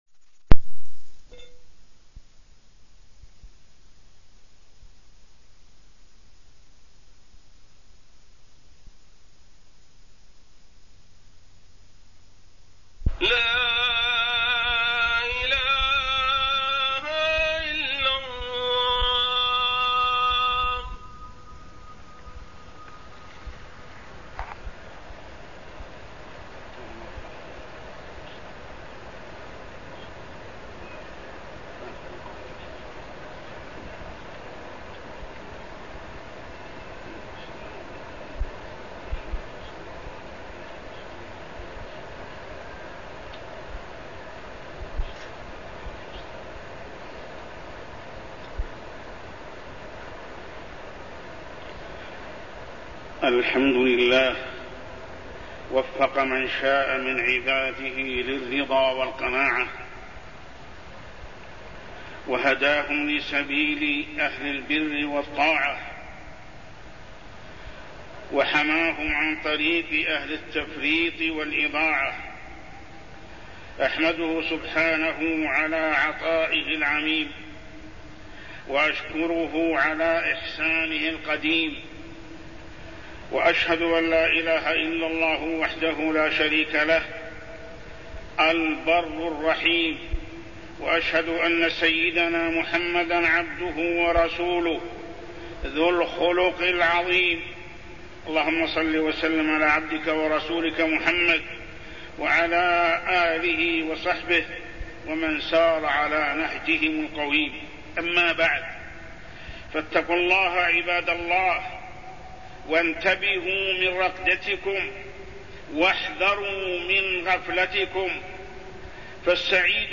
تاريخ النشر ٢٨ ربيع الأول ١٤٢١ هـ المكان: المسجد الحرام الشيخ: محمد بن عبد الله السبيل محمد بن عبد الله السبيل تحريم الكذب